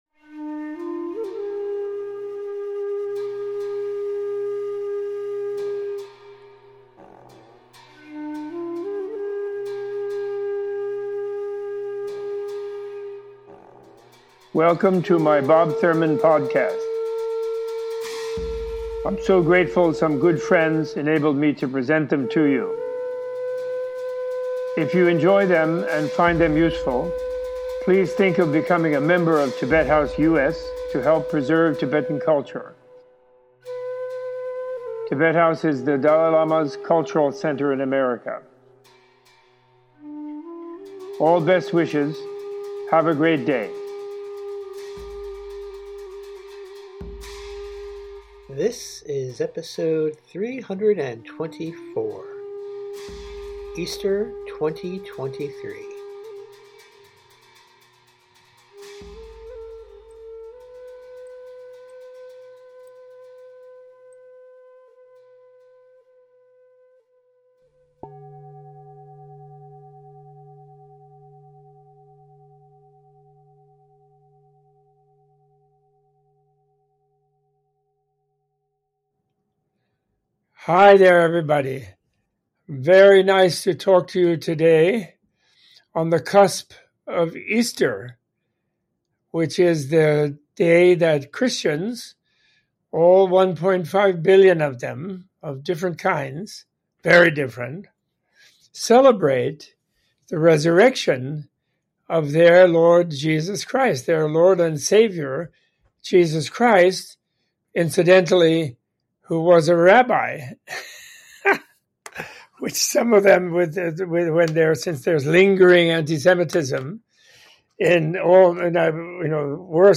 In this celebratory episode, Robert Thurman discusses the Christian holiday Easter, giving a teaching on Jesus the Rabbi and Christianity’s role in modern-day politics and everyday life in the West. Opening with a short history of early Christianity, this episode includes discussions of: the value of interfaith dialogue, the New Testament, the role of non-violence in conflict resolution, the Russian occupation and invasion of Ukraine, and how those of all backgrounds can find insight and inspiration in the story and lessons of Jesus Christ.